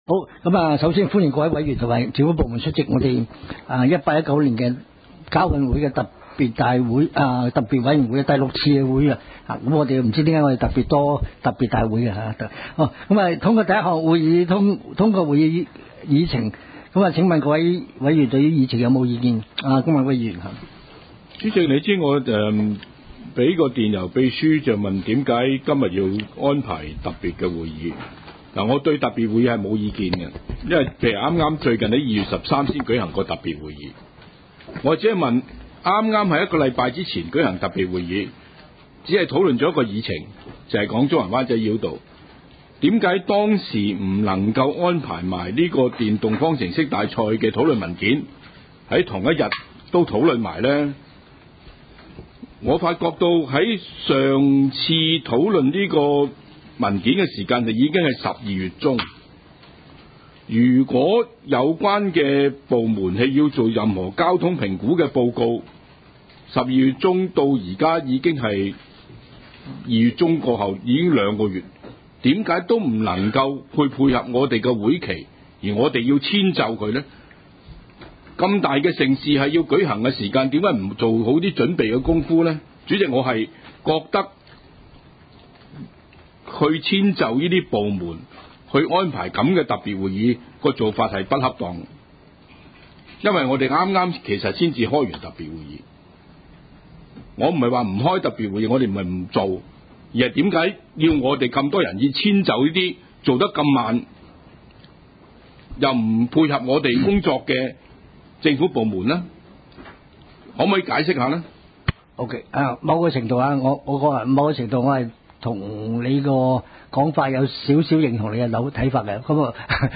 委员会会议的录音记录
地点: 香港中环统一码头道38号 海港政府大楼14楼 中西区区议会会议室